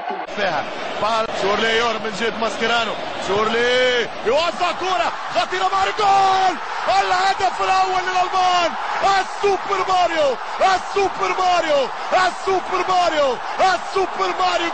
Unten findet Ihr die Reportagen zu acht berühmten Toren, bei denen sich die Kommentatoren besonders ins Zeug gelegt haben, verbunden mit jeweils einer Frage.